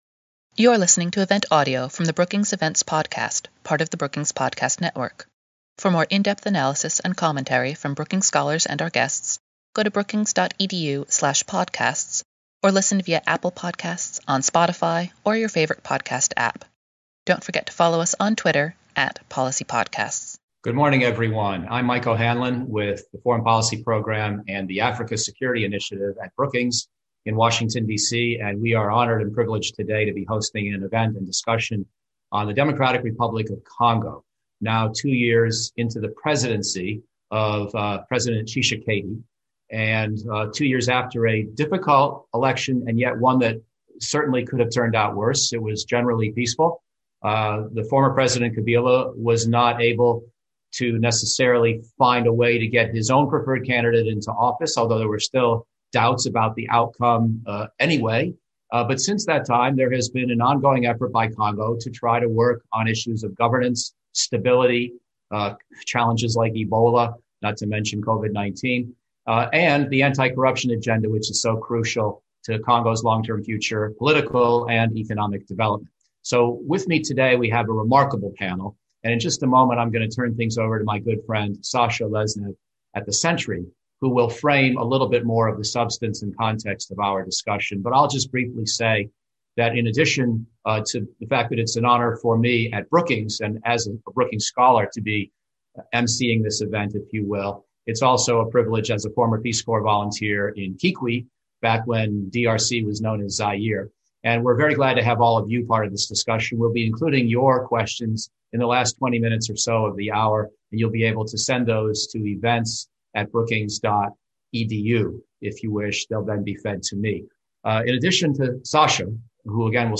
On Friday, Dec. 18, the Foreign Policy program at Brookings hosted a panel discussion examining the issues and the possible U.S. policy options to address them.